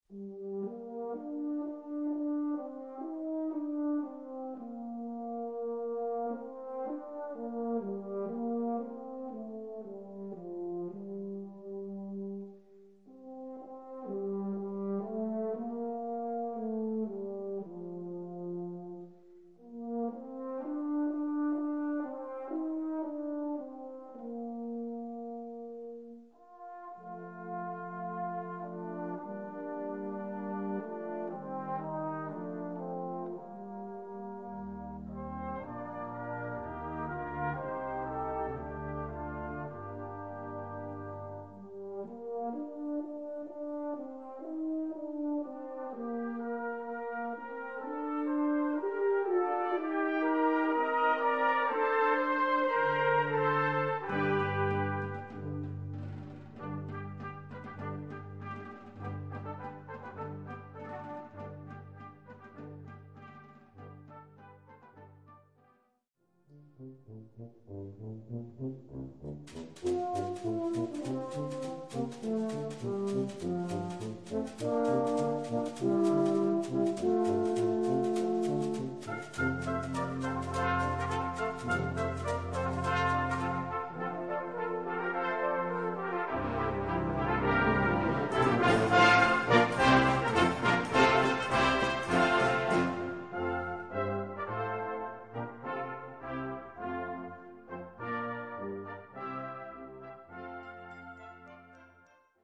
Gattung: Suite
8:00 Minuten Besetzung: Blasorchester PDF